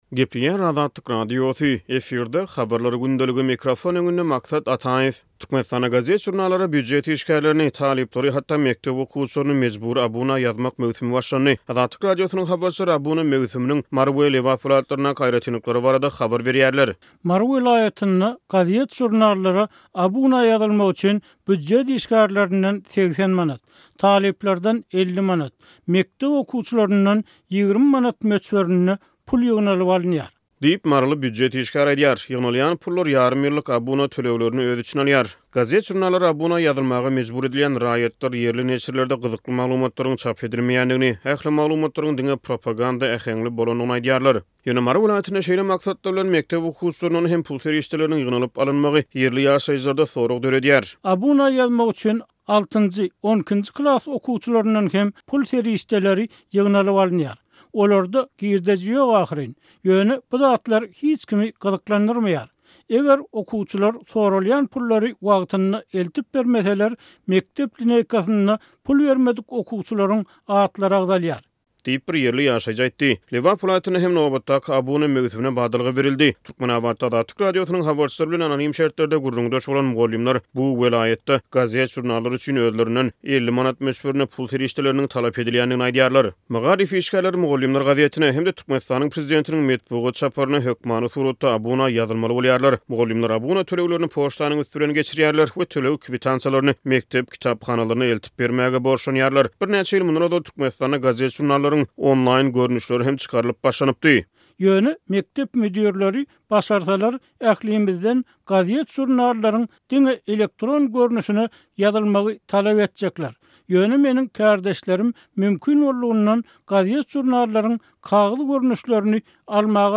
Türkmenistanda gazet-žurnallara býujet işgärlerini, talyplary hatda mekdep okuwçylaryny mejbury abuna ýazmak möwsümi batlandy. Azatlyk Radiosynyň habarçylary abuna möwsüminiň Mary we Lebap welaýatlaryndaky aýratynlyklary barada habar berýärler.